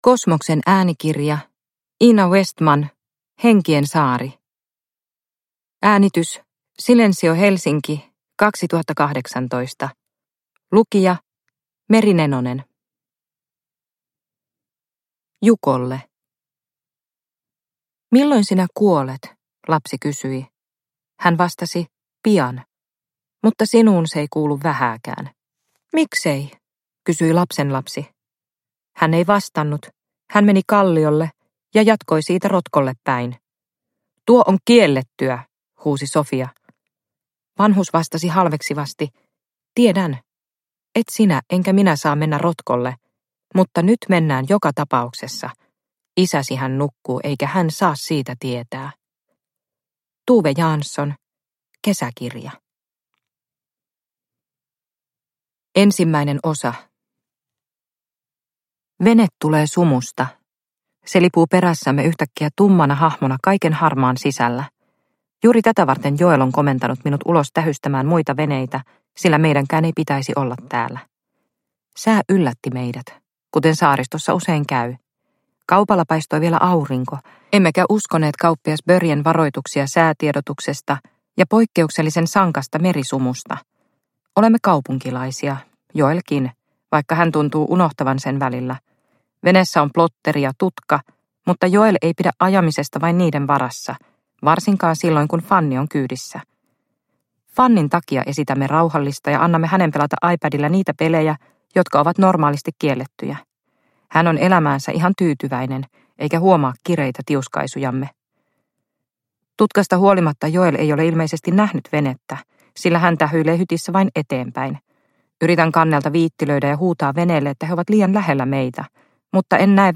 Henkien saari – Ljudbok – Laddas ner